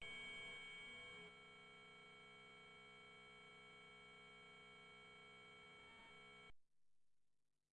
Korg Z1 8 bit Piano " Korg Z1 8 bit Piano F7（8 bit Piano10390
描述：通过Modular Sample从模拟合成器采样的单音。
标签： MIDI-速度-63 FSharp7 MIDI-笔记记录103 Korg的-Z1 合成器 单票据 多重采样
声道立体声